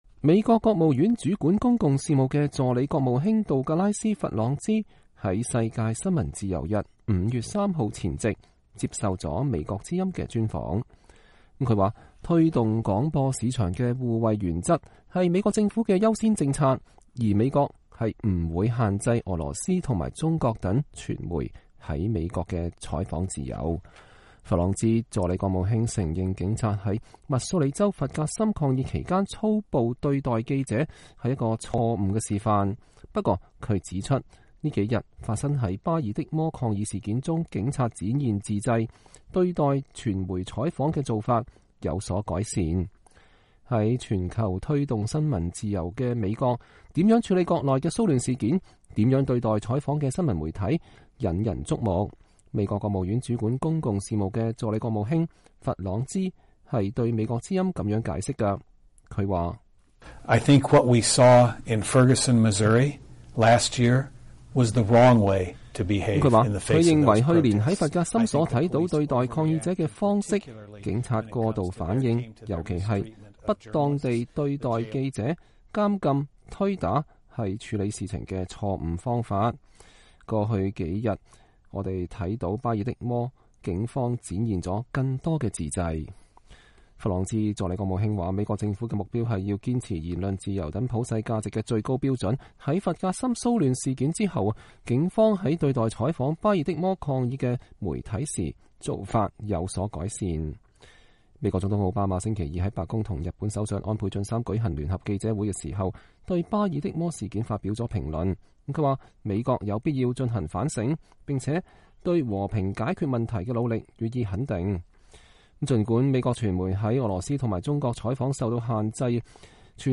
專訪美國國務院助卿談新聞自由：弗格森與巴爾的摩